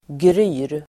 Uttal: [gry:r]